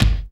SWING BD 10.wav